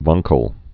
(vängkəl, wäng-, wăng-)